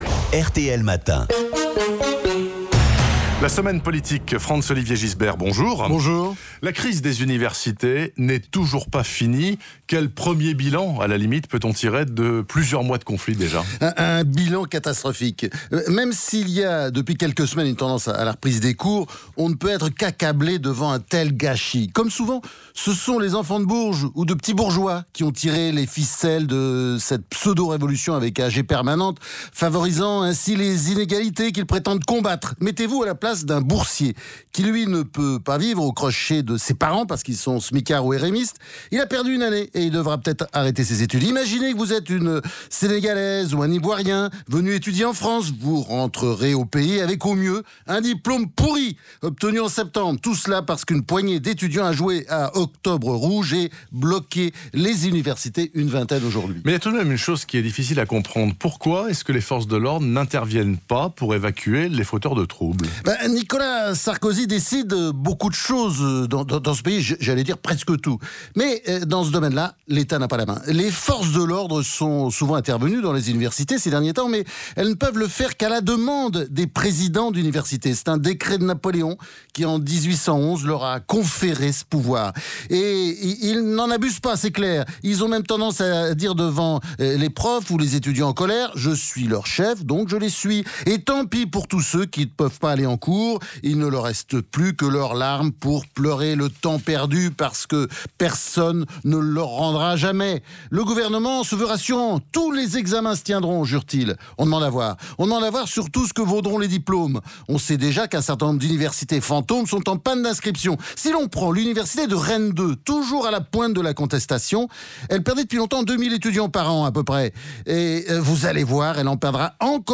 Sur RTL, le grand FOG confie à qui a le courage de l’écouter sa « semaine politique ». Celle du 16 mai tire le bilan du mouvement universitaire, avec la mesure et l’honnêteté intellectuelle qui le caractérisent.